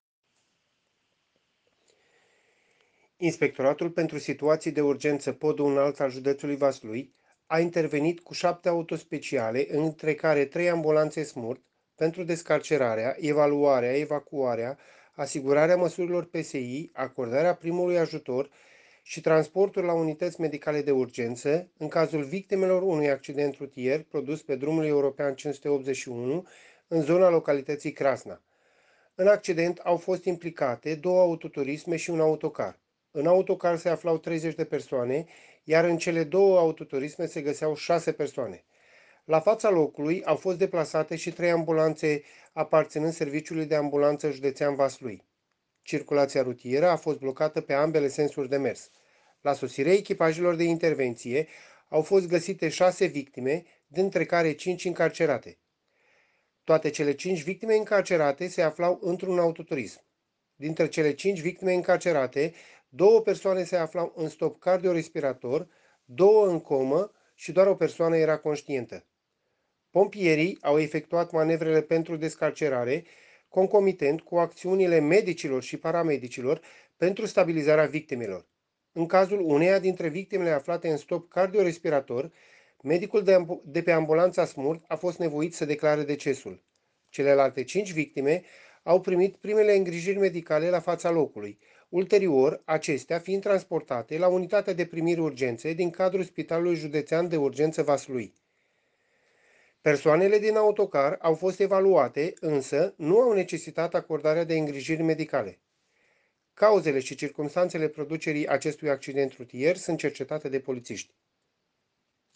Declarație